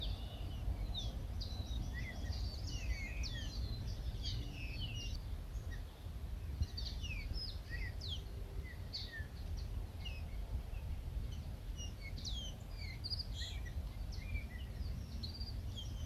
Colorada (Rhynchotus rufescens)
Nombre en inglés: Red-winged Tinamou
Localidad o área protegida: Reserva Natural Paititi
Condición: Silvestre
Certeza: Observada, Vocalización Grabada